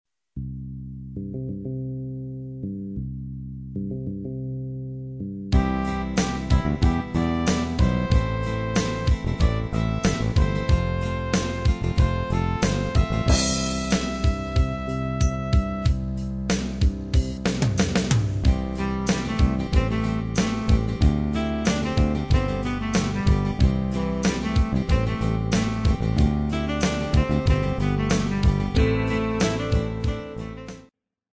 Category: Pop Tag: 60s Rock